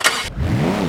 Index of /server/sound/vehicles/sgmcars/997
start.wav